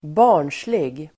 Uttal: [²b'a:r_nslig]